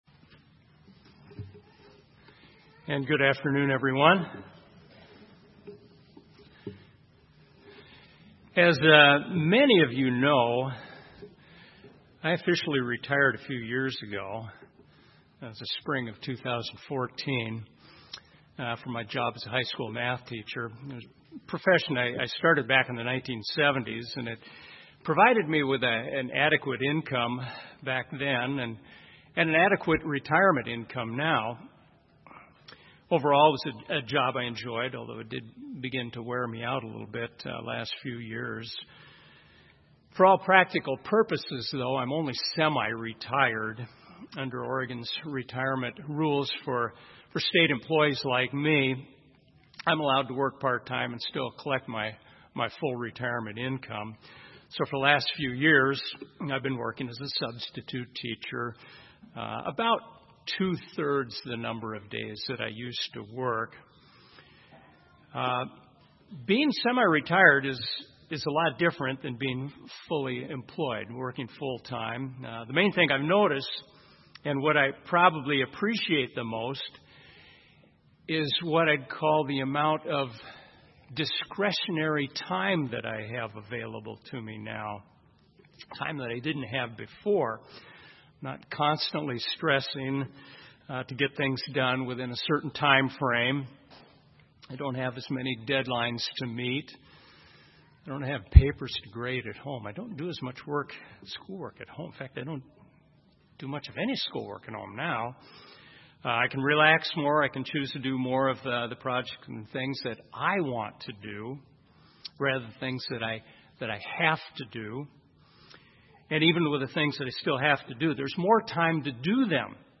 Sermons
Given in Salem, OR